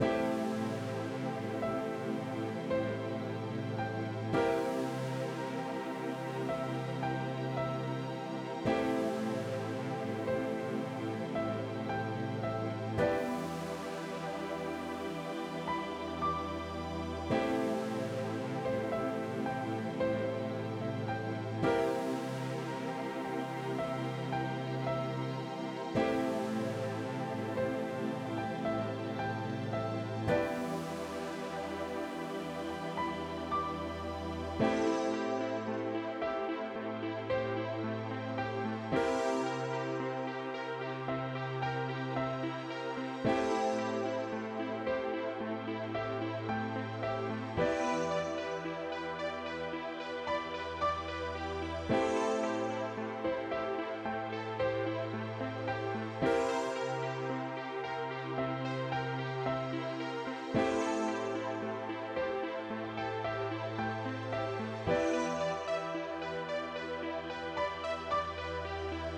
Golden rose_111bpm.wav